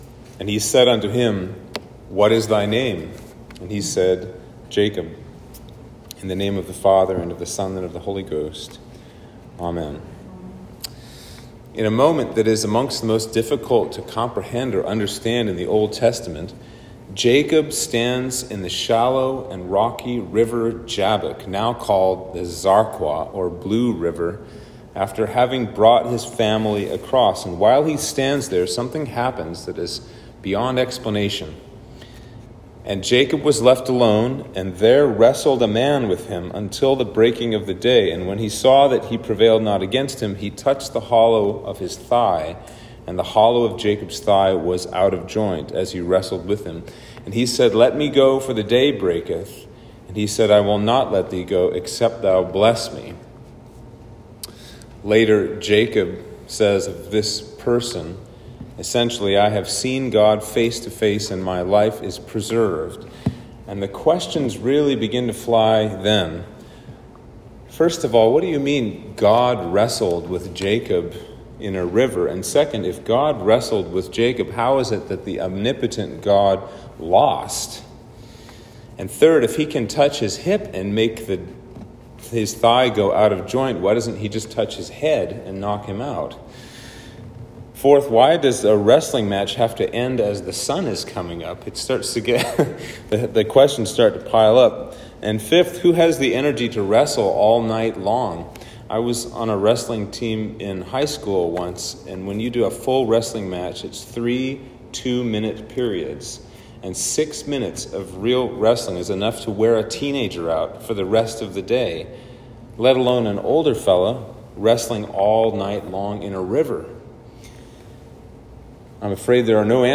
Sermon for Trinity 10